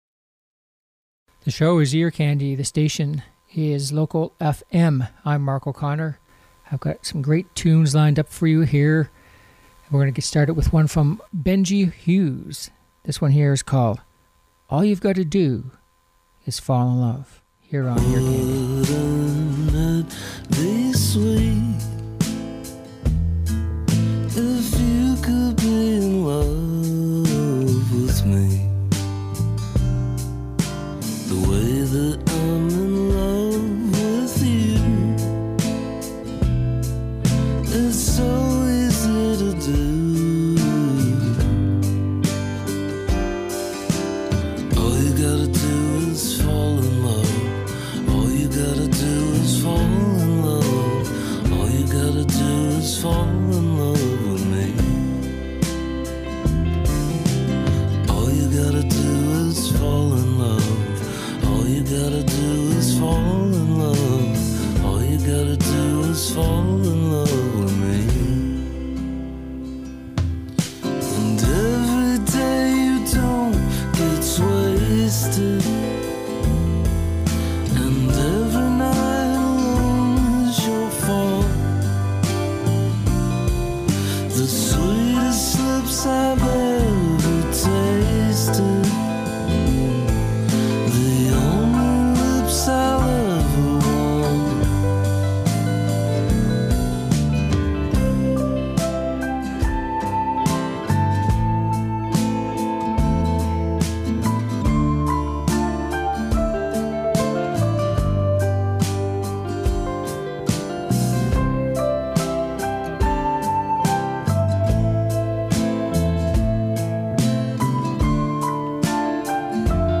Upbeat Pop Songs